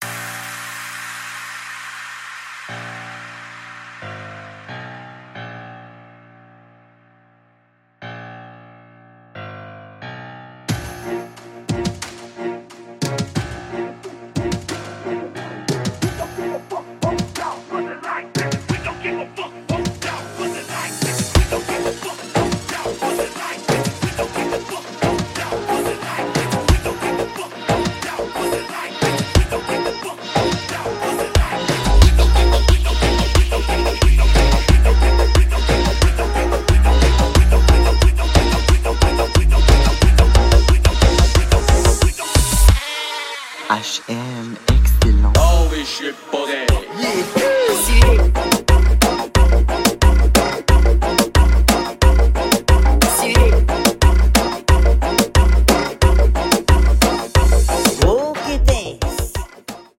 MUSIC REMIX 2021